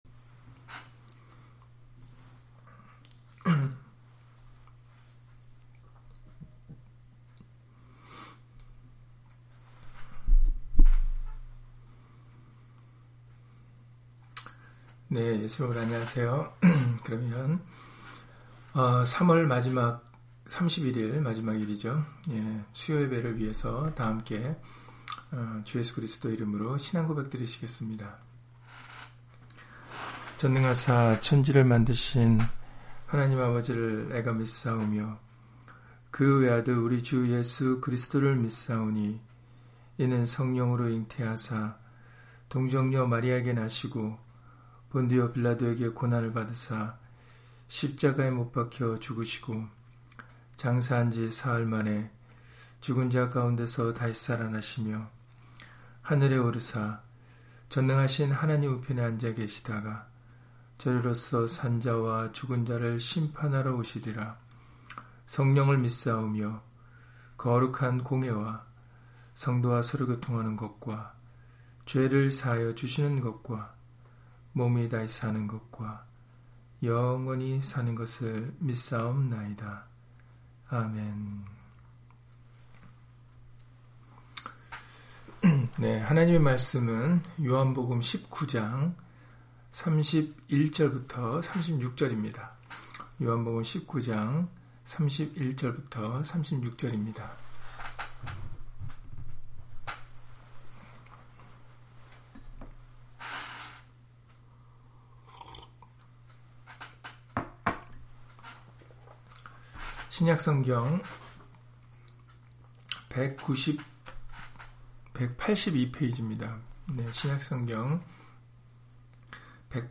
요한복음 19장 31-36절 [너희로 믿게 하려함] - 주일/수요예배 설교 - 주 예수 그리스도 이름 예배당